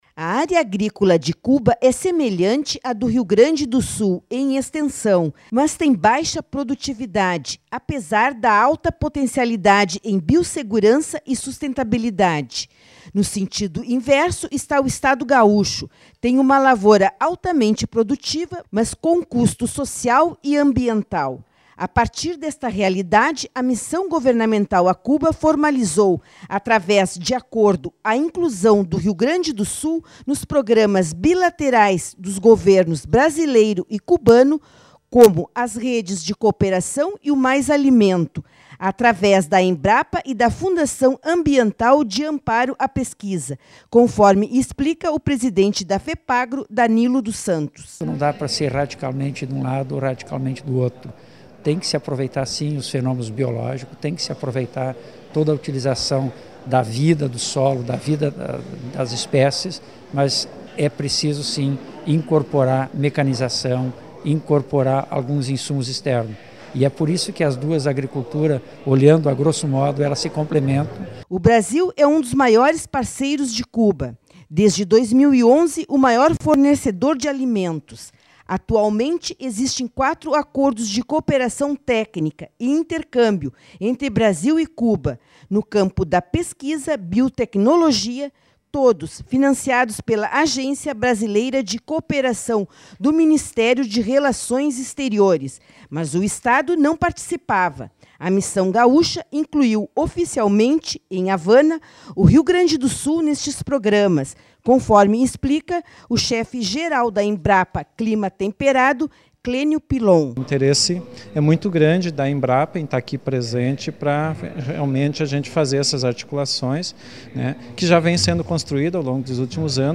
boletim-cuba-agricultura.mp3